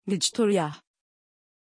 Pronunciation of Victoriah
pronunciation-victoriah-tr.mp3